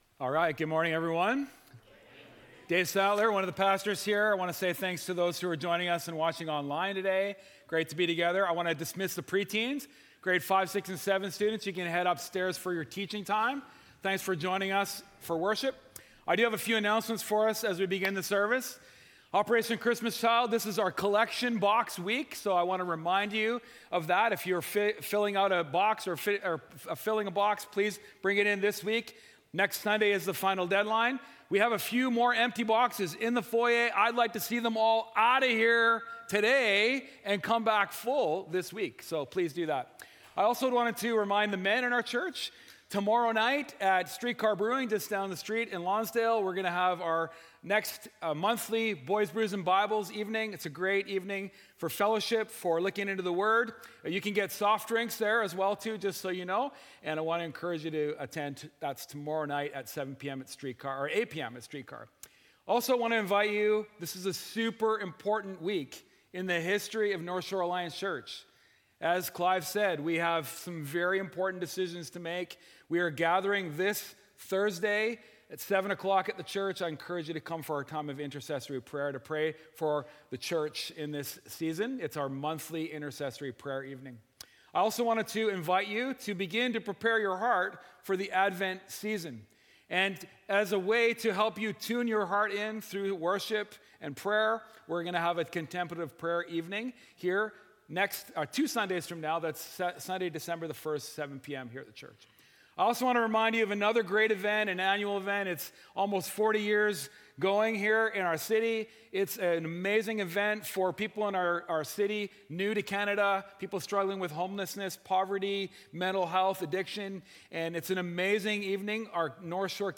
Sermons | North Shore Alliance Church